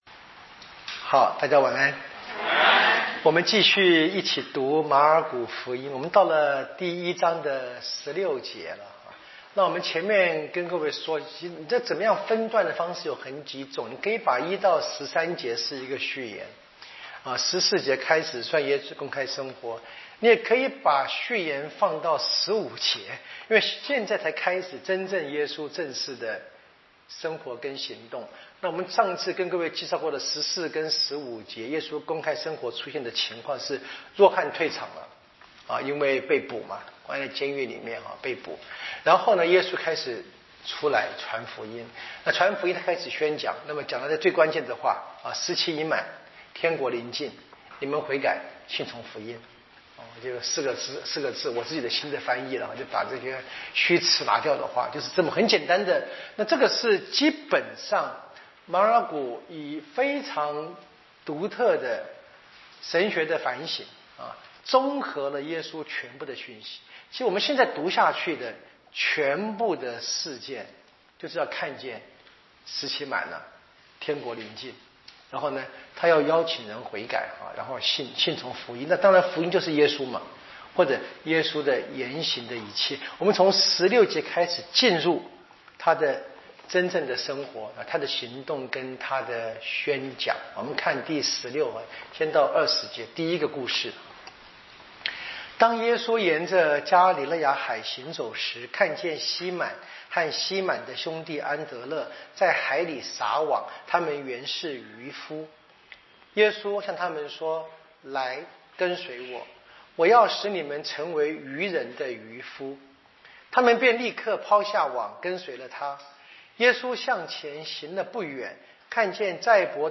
【圣经讲座】《马尔谷福音》